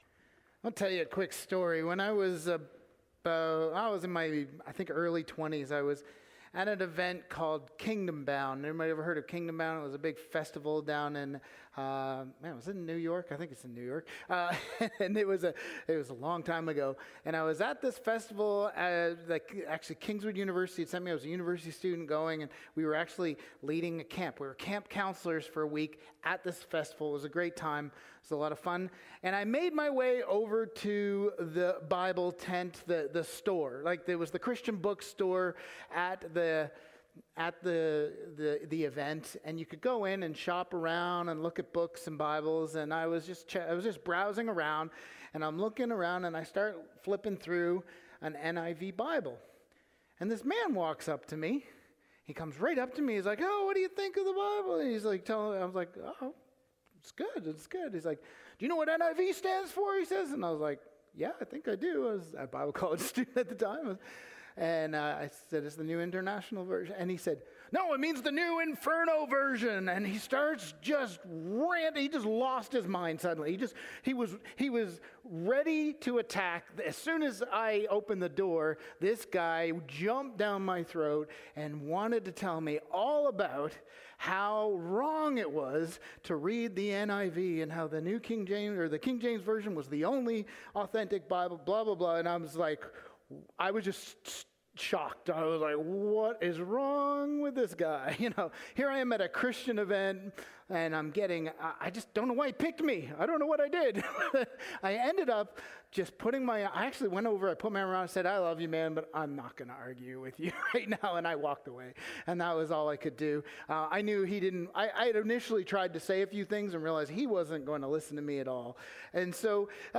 Sermons | Sunnyside Wesleyan Church